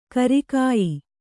♪ kari kāyi